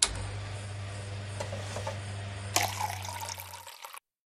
dispense1.ogg